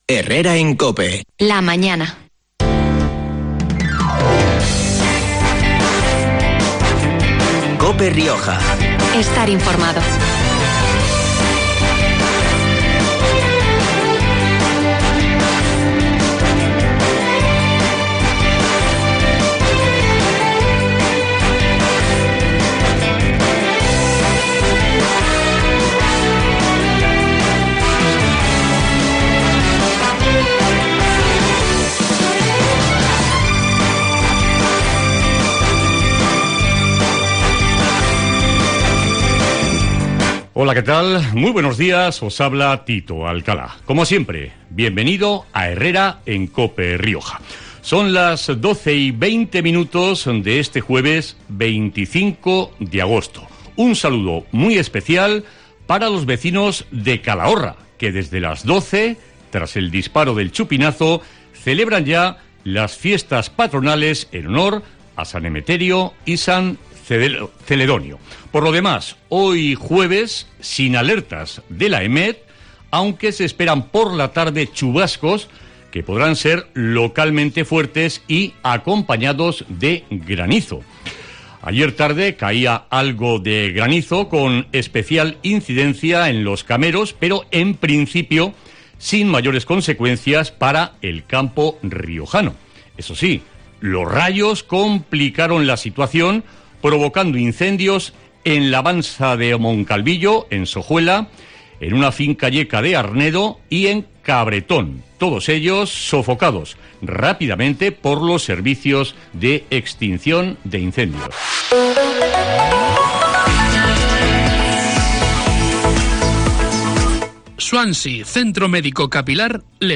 Como cada jueves, hemos emitido en “Herrera en COPE Rioja” el espacio patrocinado por La Rioja Turismo en el que, durante este verano, estamos poniendo en valor los numerosos recursos turísticos que atesora nuestra comunidad autónoma. Hoy los protagonistas han sido los Cameros, tanto el Nuevo, como el Viejo.